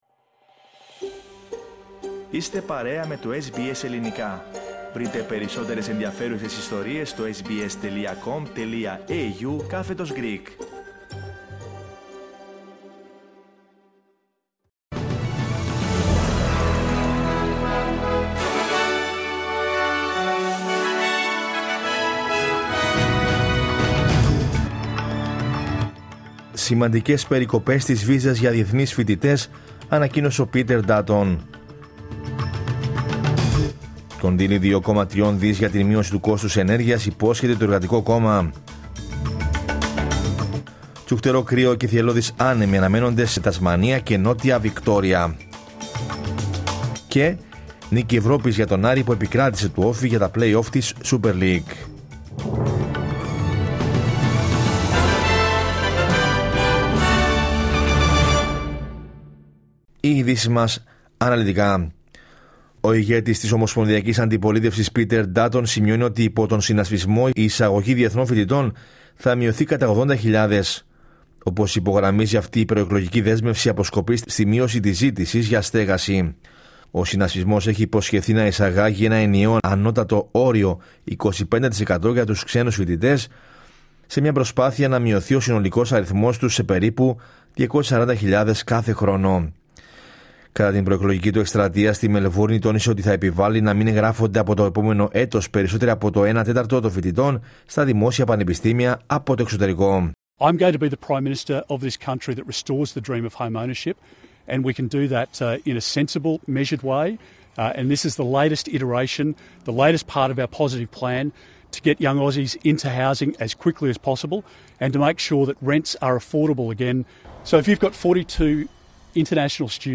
Δελτίο Ειδήσεων Κυριακή 6 Απριλίου 2025